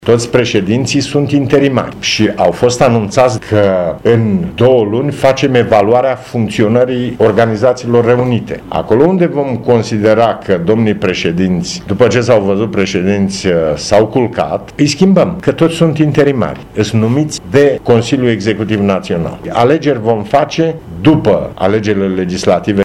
Președintele PMP, Traian Băsescu, a precizat la Poiana Brașov că procesul de fuziune între PMP și UNPR, la nivel central și în teritoriu, s-a încheiat.